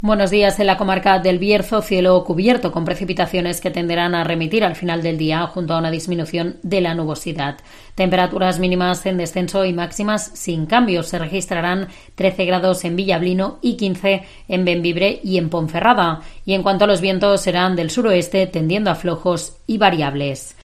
AUDIO: Previsión meteorológica para esta jornada de la mano de la Agencia Estatal de Meteorología (AEMET)